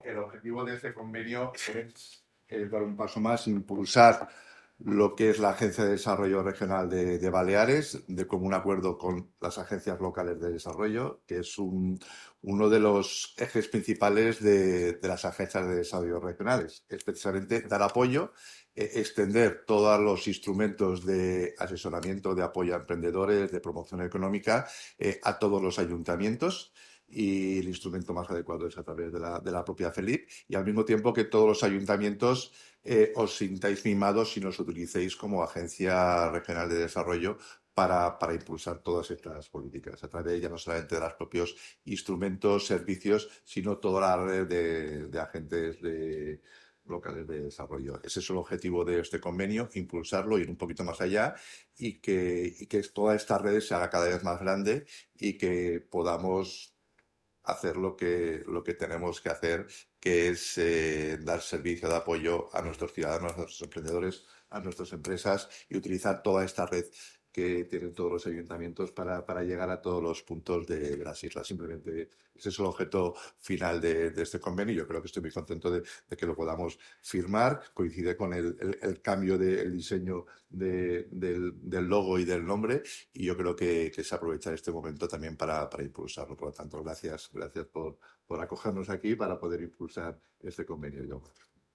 Declaraciones conseller Alejandro Sáenz de San Pedro